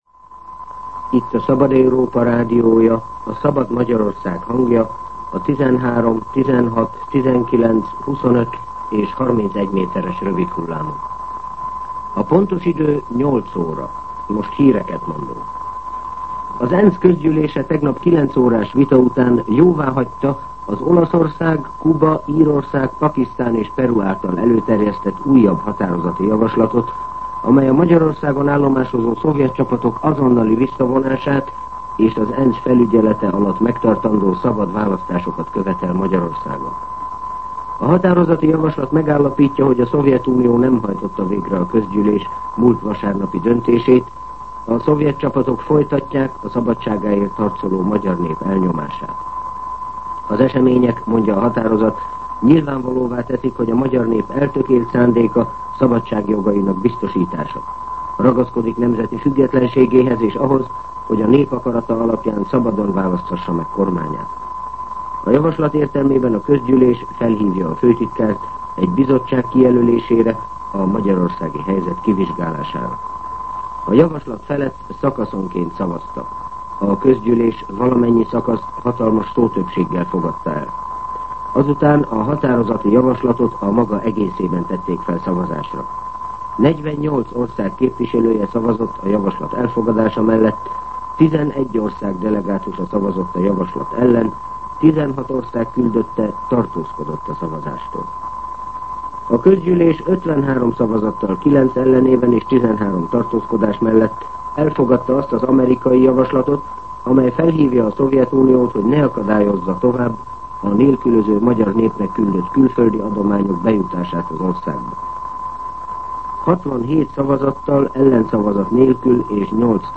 08:00 óra. Hírszolgálat